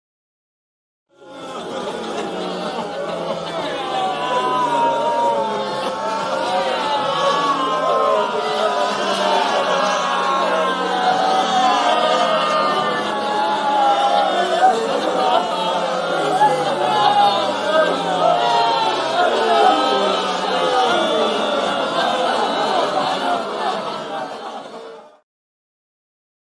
جلوه های صوتی
دانلود صدای گریه تماشاگران و حضار از ساعد نیوز با لینک مستقیم و کیفیت بالا